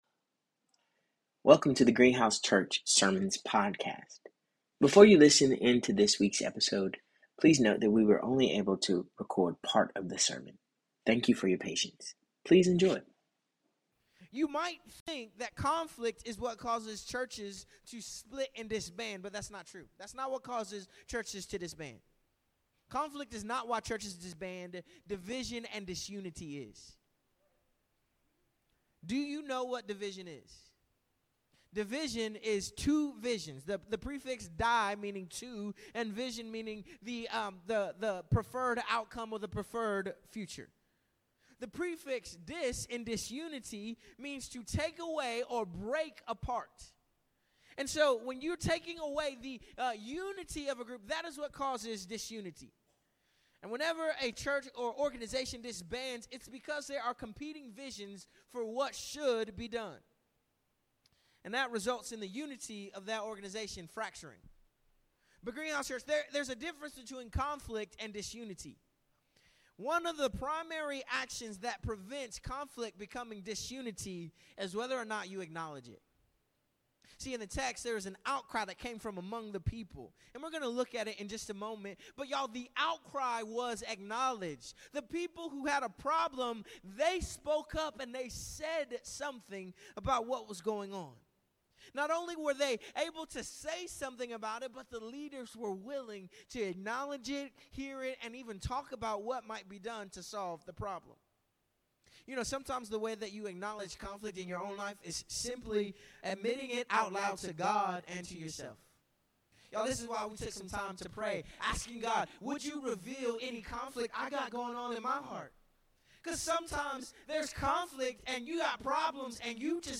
Before you listen in to this week’s sermon, please note that we were only able to record part of the sermon.
This sermon was originally delivered on July 29th, 2025.